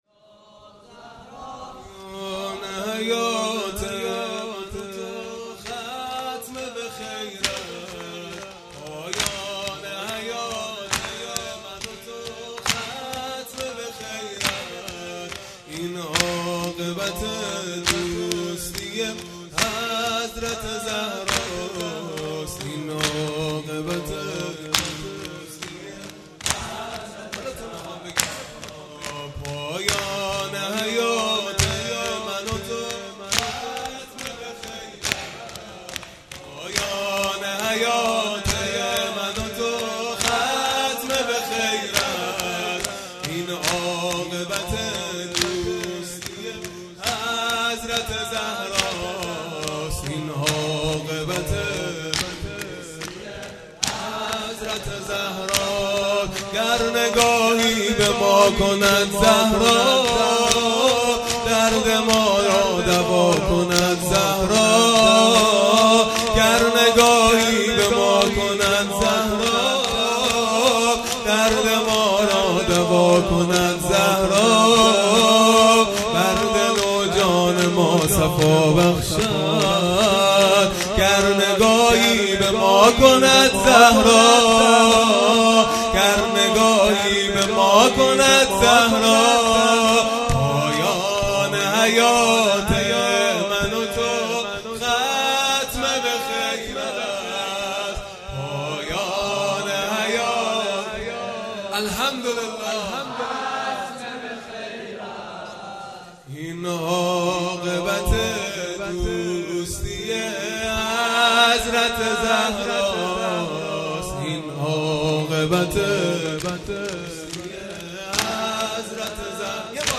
خیمه گاه - هیئت بچه های فاطمه (س) - شور پایانی | پایان حیات من و تو ختم به خیر است
فاطمیه دوم(شب سوم)